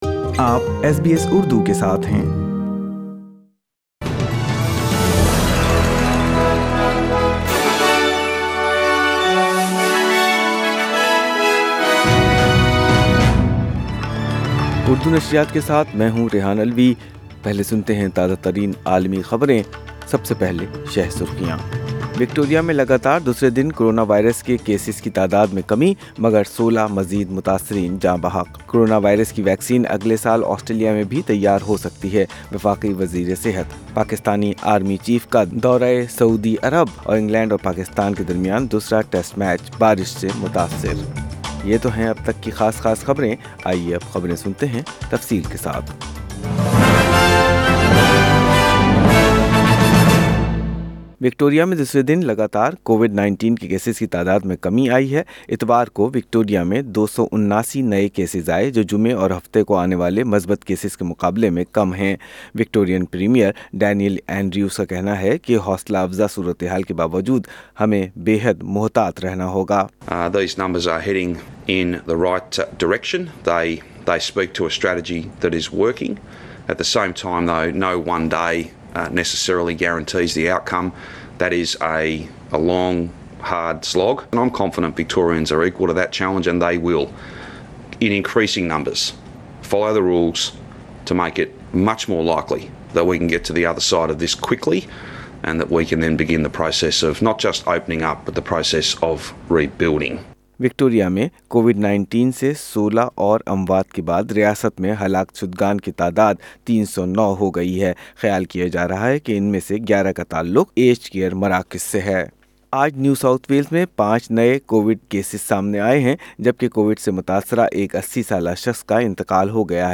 اردو خبریں اتوار 16 اگست 2020